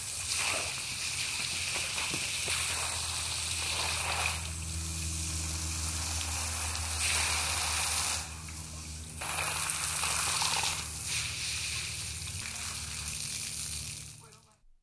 Garden watering
Just me and my pink hose having a blast.
s_garden_watering_wgsd22.wav